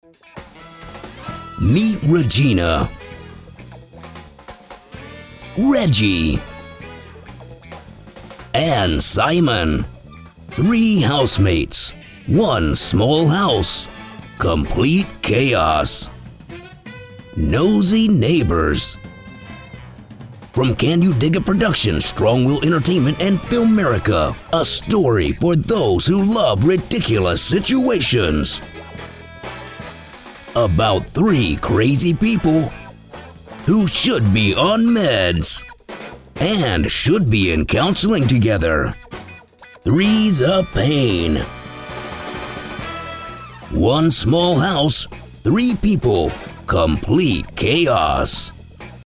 Male
Radio / TV Imaging
Amazon Tv Show Sizzle Trailer